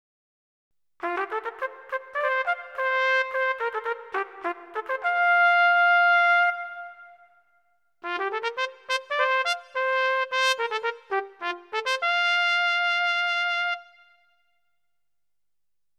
In den folgenden Klangbeispielen spiele ich zuerst immer die Yamaha Genos Voice, danach die V3 YAMMEX Sounds.
Mehr Attack verhilft dem Cornet des V3 YAMMEX zu mehr Ausdrucksmöglichkeiten.